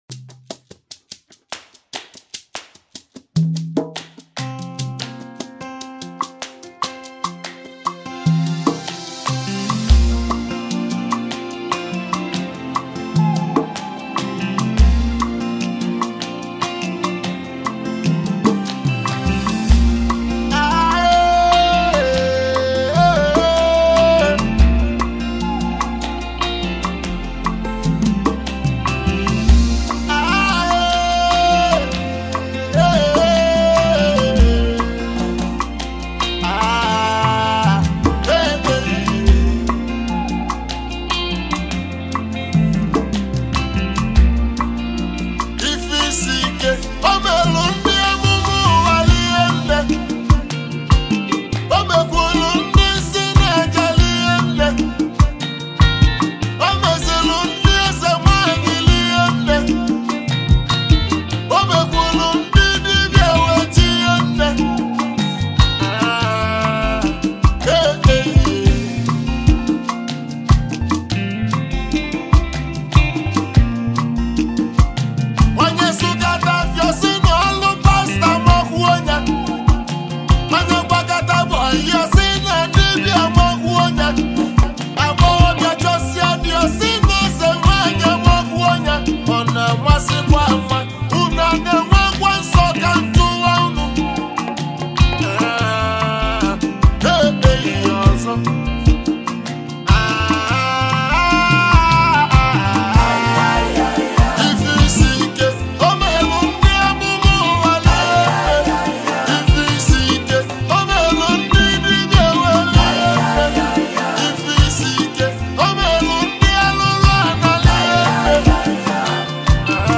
Highlife Music
Popular Nigerian highlife music duo